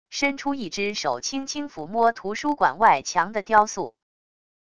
伸出一只手轻轻抚摸图书馆外墙的雕塑wav音频